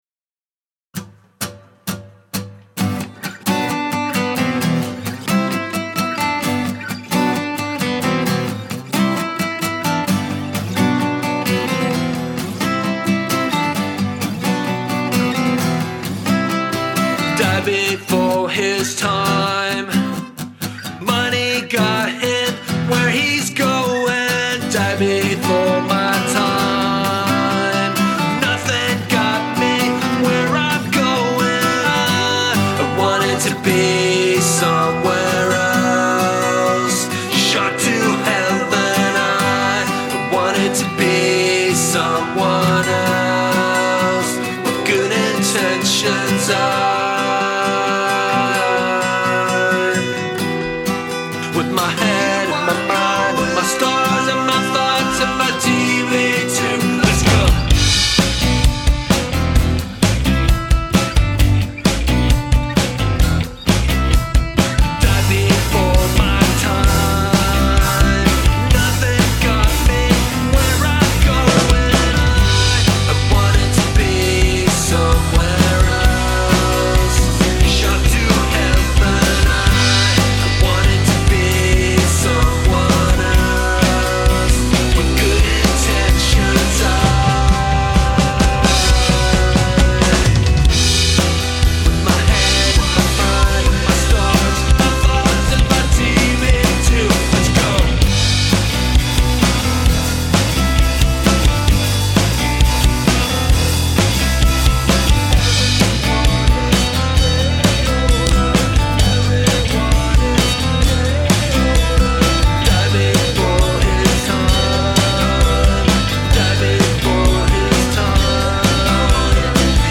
Punk gone acoustic - Original Tune (Everyone is Getting Older)
I threw Drums and Bass on there.
I even jumped in with snare and floor tom accented on “Let’s Go!” just for you! Probably not a great mix at this point; my laptop is so bogged down the CPU meter sits at 93% without the song even playing…ugh!